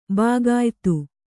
♪ bāgāytu